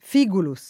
f&guluS] «vasaio» e conservata nel nome d’un paese del Senese meridionale